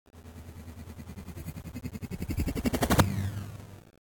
warp_in.ogg